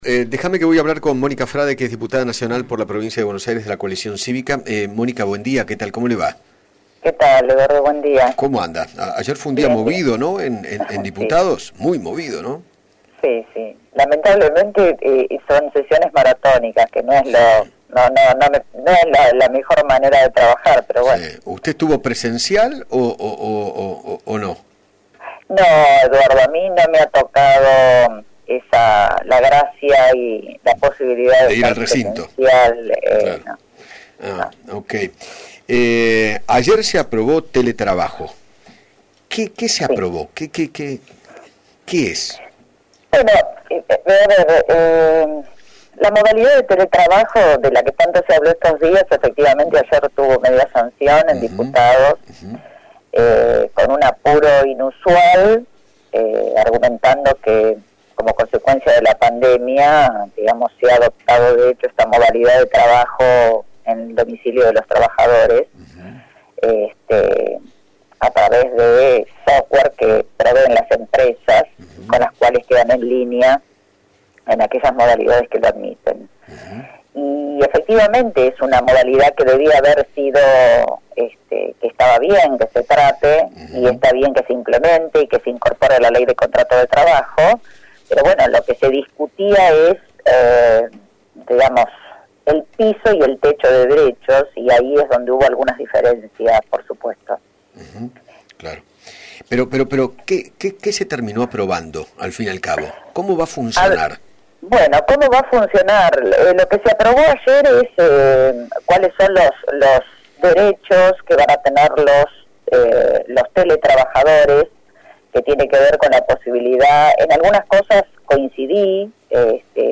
Mónica Frade, diputada Nacional, dialogó con Eduardo Feinmann sobre el proyecto que fue aprobado ayer en la Cámara de Diputados para regular la modalidad de trabajo a distancia.